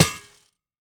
Monster_Spawner_break4_JE1_BE1.wav